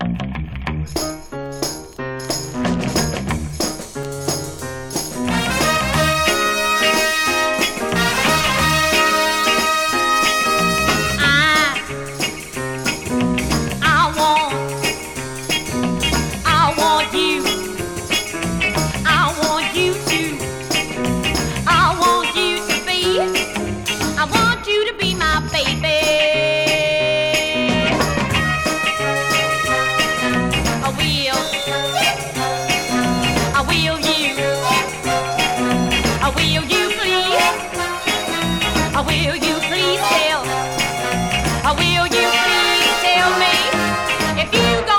本盤でも、高い表現力で魅力的なボーカルを披露。
Pop, Soul　USA　12inchレコード　33rpm　Stereo